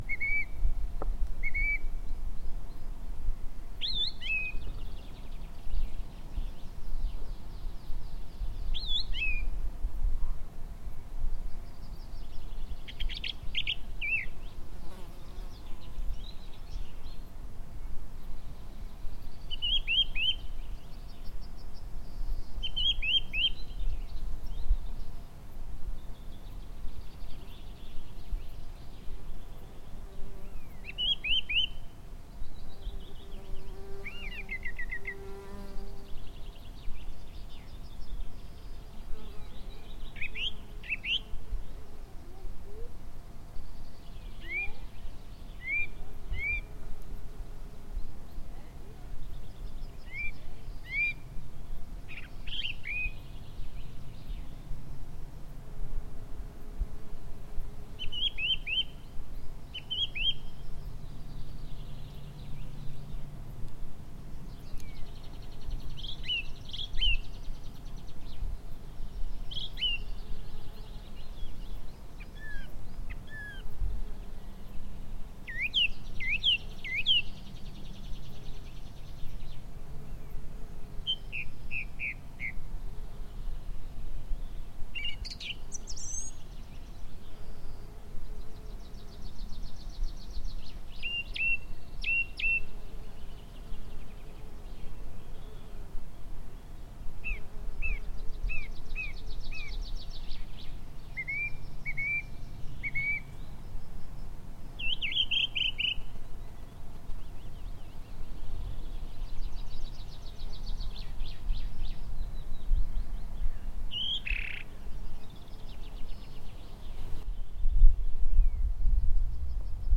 Grive musicienne
Comme l’indique son nom, son chant est très varié et mélodieux : Chant de la Grive_musicienne
STE-003Grive_musicienne.mp3